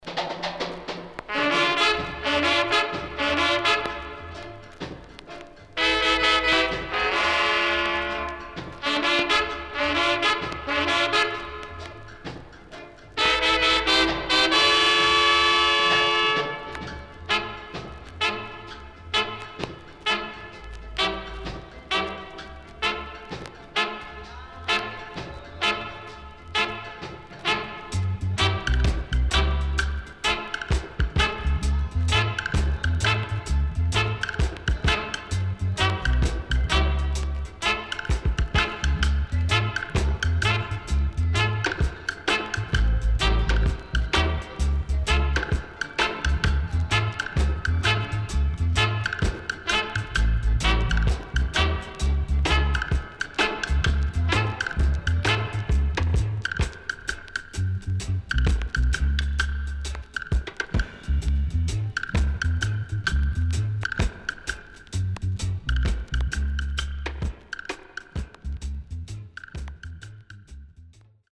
HOME > REGGAE / ROOTS
SIDE A:所々プチノイズ入ります。